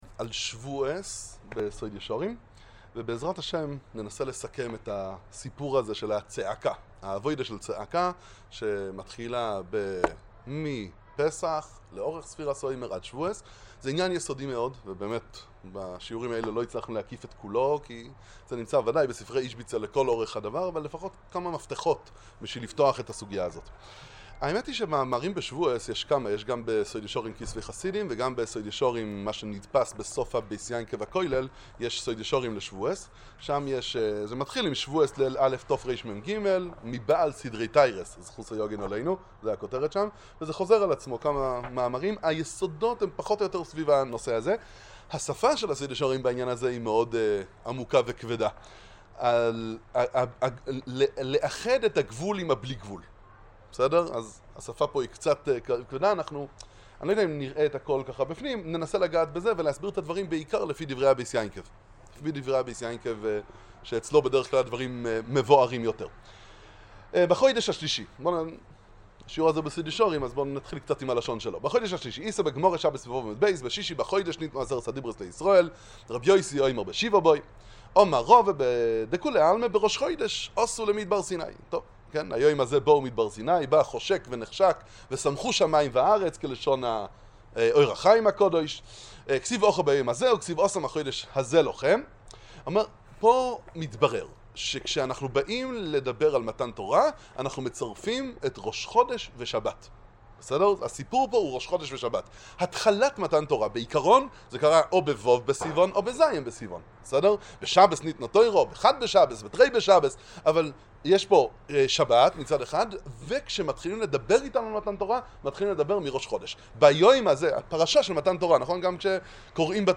שיעור רביעי בסדרה